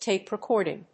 アクセントtápe recòrding